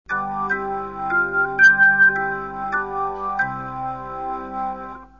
ZESTAWY GŁOŚNIKOWE LESLIE
"Leslie" Elkatone + Roland JV880.
1. Obroty rotorów wyłączone - "
OFF" - dźwięk "czysty"( plik w formacie MP3 - 20,1 KB )